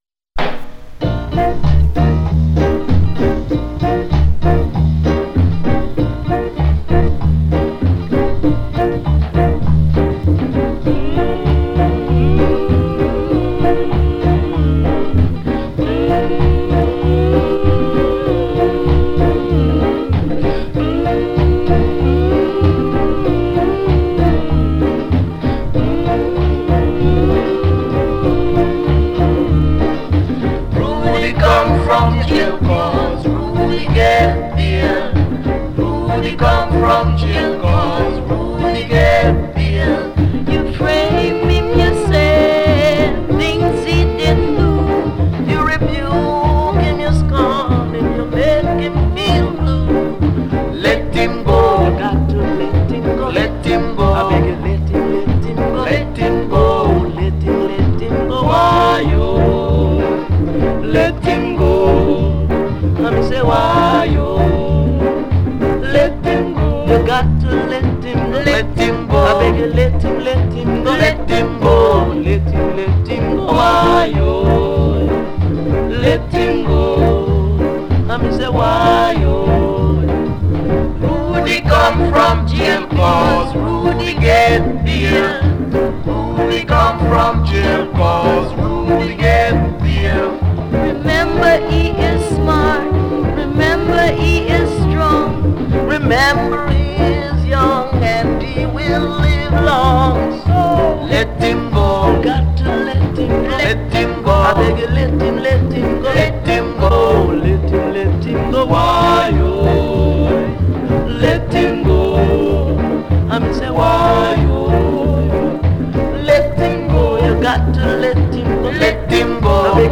Rockin’ Steady social commentary